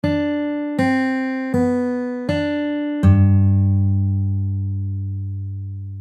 • the bass note is fingered with the 3rd finger of the left hand (the ring finger) and plays the 6th string (thickest string) at the 3rd fret
• there are then 3 notes on the B string (the second thinnest string) at the 3rd fret (played with the pinky of the left hand)
Travis picking pattern | learn fingerpicking | travis picking in G | exercise 1